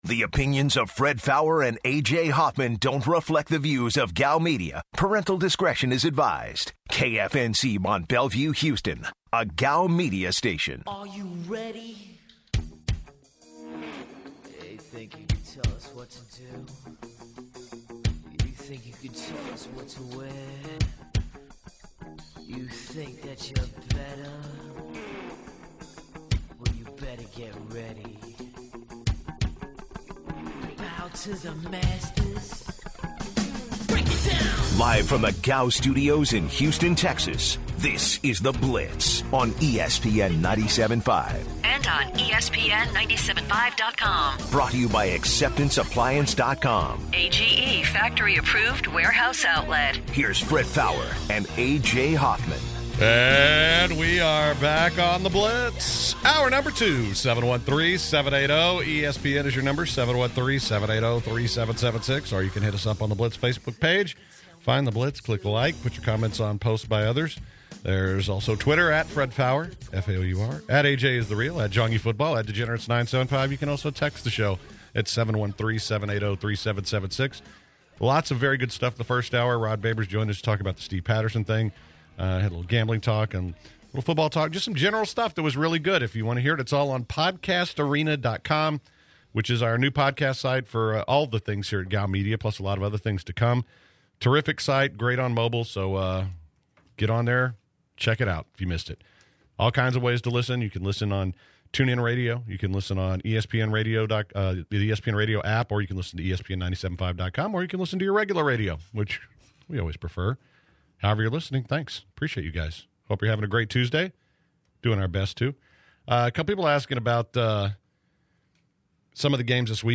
To open the second hour, the guys take calls from fellow blitzers.